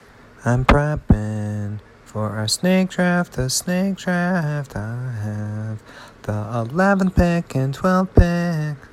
c g d F